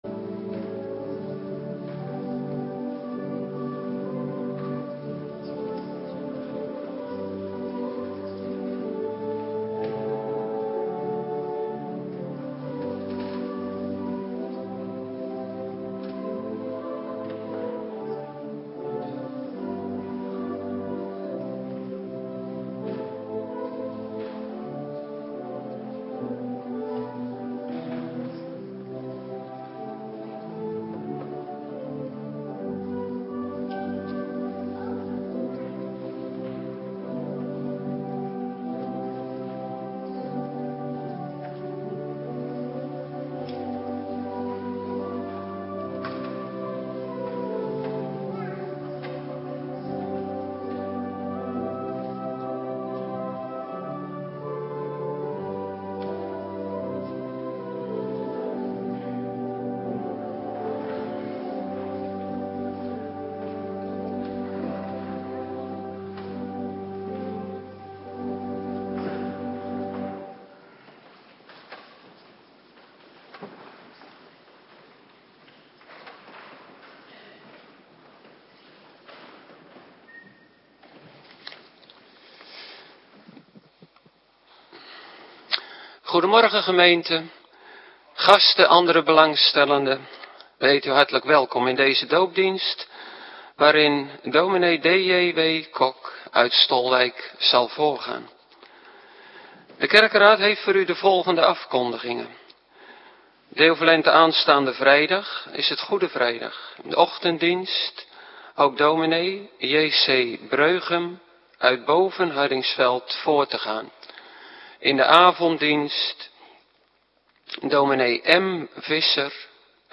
Morgendienst Heilige Doop - Cluster 1
Locatie: Hervormde Gemeente Waarder